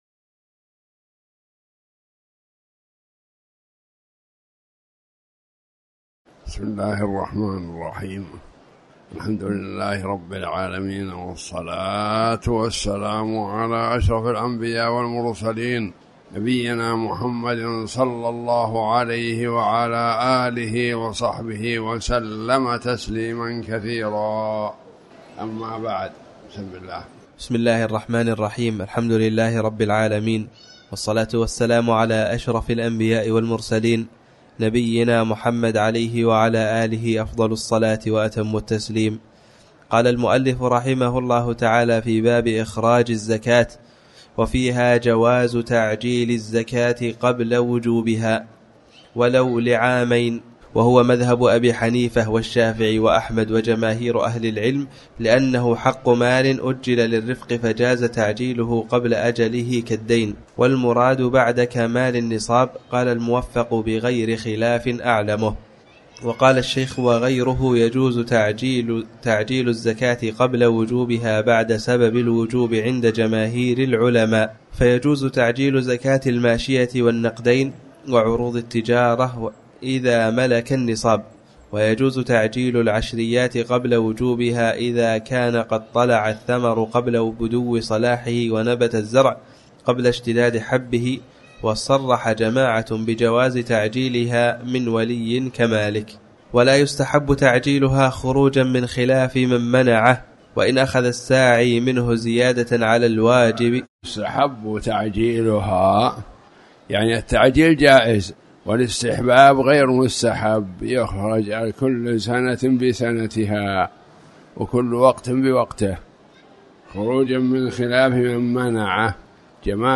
تاريخ النشر ١ ربيع الأول ١٤٤٠ هـ المكان: المسجد الحرام الشيخ